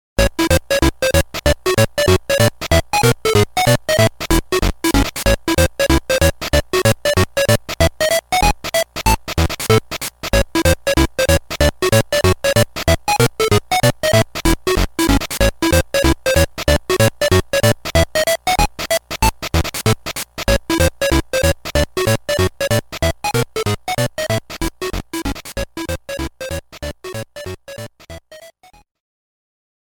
The theme